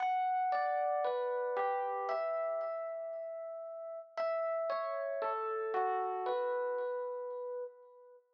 01 ElPiano PT2.wav